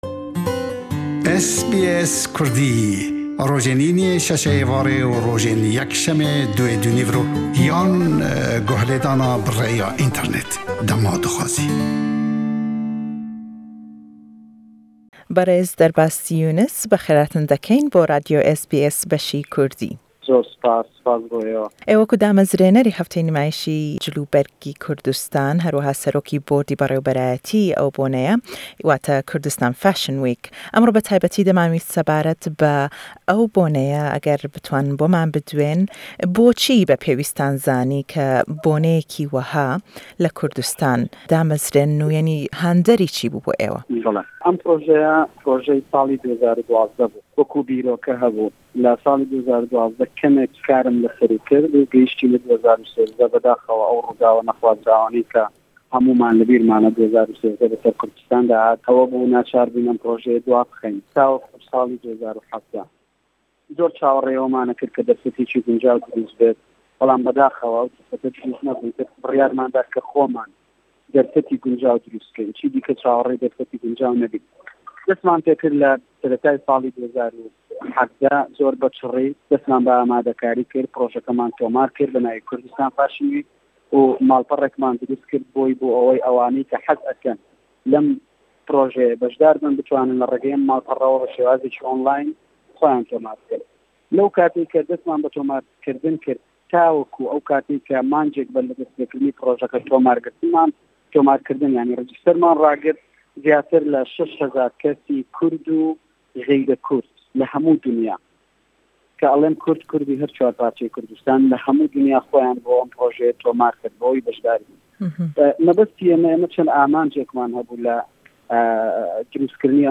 Le em lêdwane da